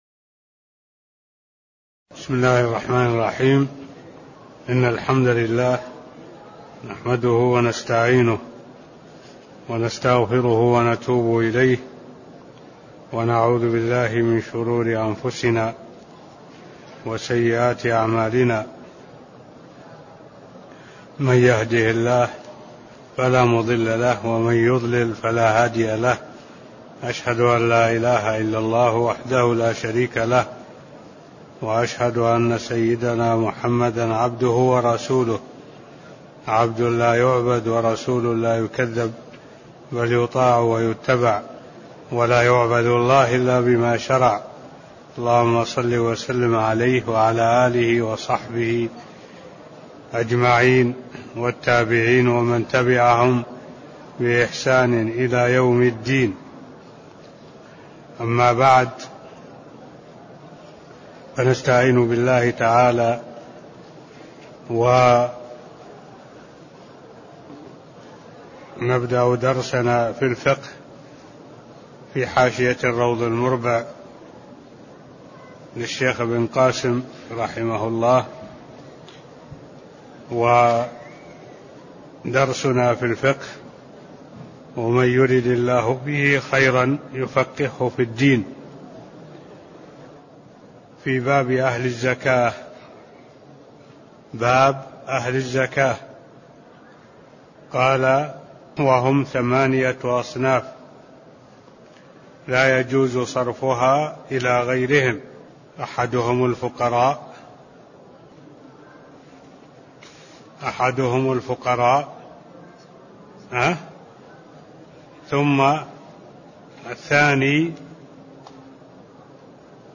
تاريخ النشر ٢٢ ربيع الثاني ١٤٢٧ هـ المكان: المسجد النبوي الشيخ